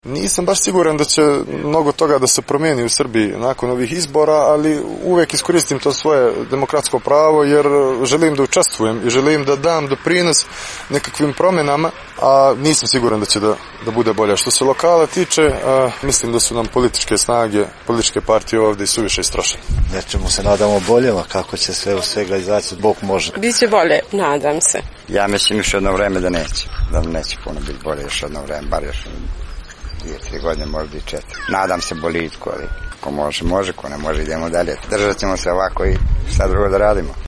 Anketa: Građani Novog Pazara o izbornim očekivanjima